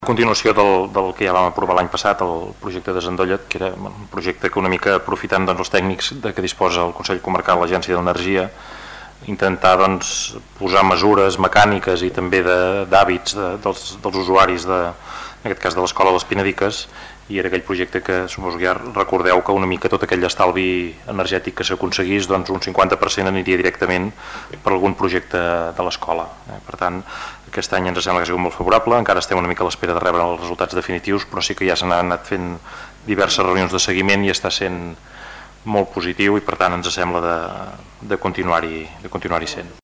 L'alcalde,